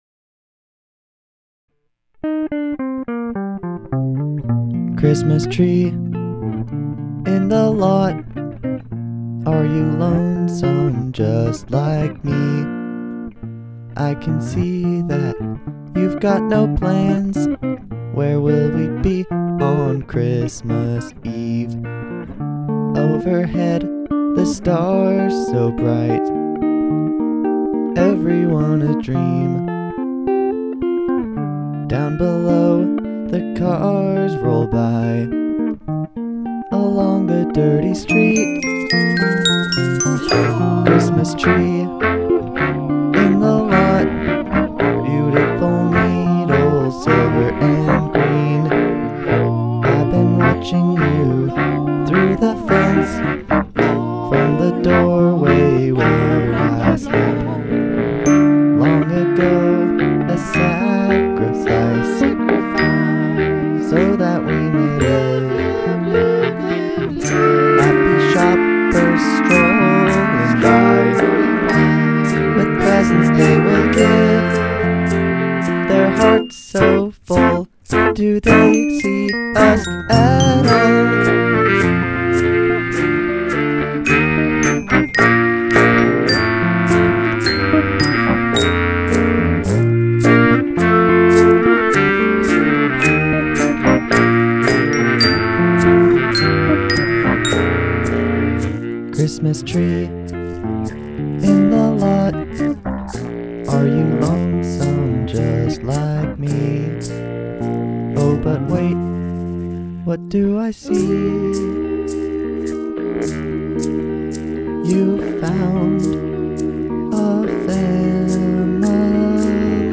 featuring the Bass Clarinet prominently.